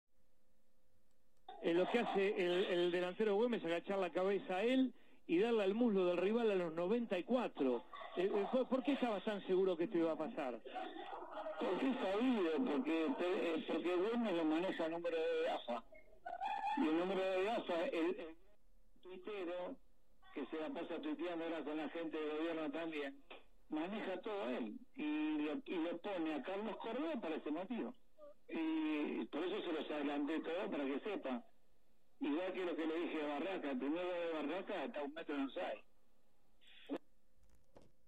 Ricardo Caruso Lombardi, en la antesala de este duelo, avisó lo que podía suceder: «La que te espera», le dijo a Alvarado. Y luego del duelo, habló con «La Voz del Estadio» en exclusiva y explicó los motivos de aquel aviso.